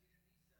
2025 The Life of Noah Geneis 6:8-7:16 The next sermon in our series examining the life of Noah seeing how both God's mercy and judgment were present for Noah and for us.